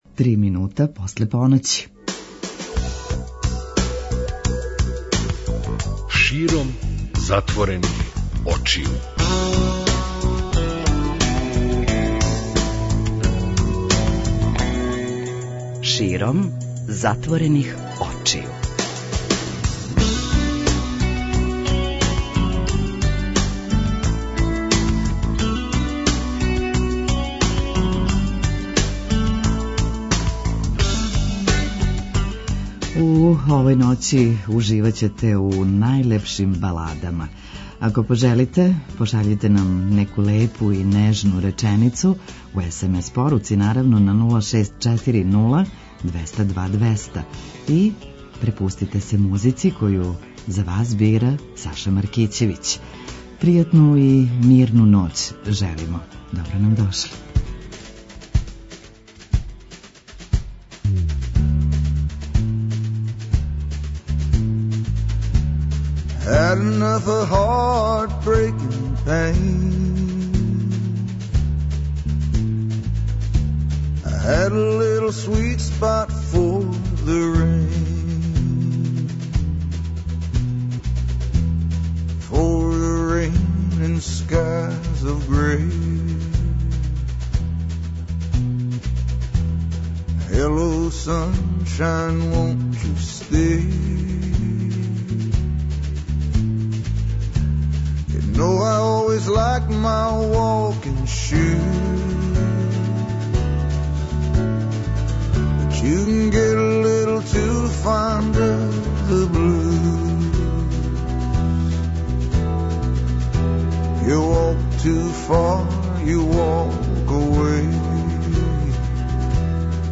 преузми : 55.45 MB Широм затворених очију Autor: Београд 202 Ноћни програм Београда 202 [ детаљније ] Све епизоде серијала Београд 202 Устанак Брза трака Брза трака: Млади у саобраћају Како сте спавали?